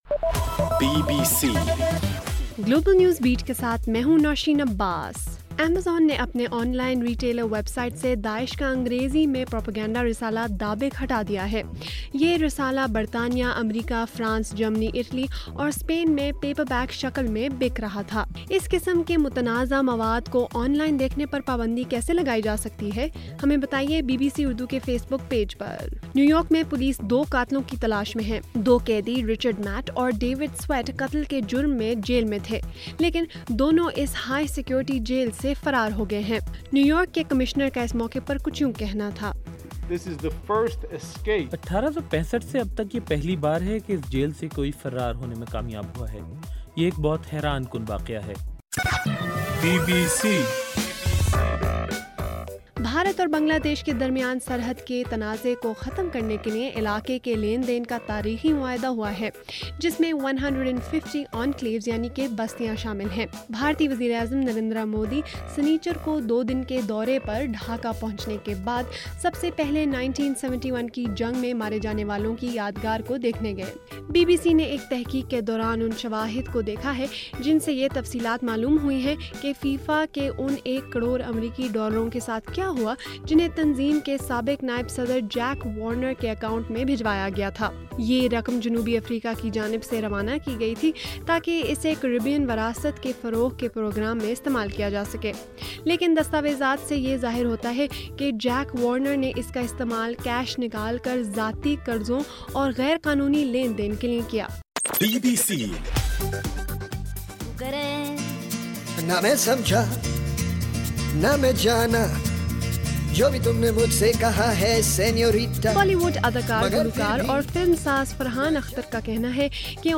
جون 7: رات 8 بجے کا گلوبل نیوز بیٹ بُلیٹن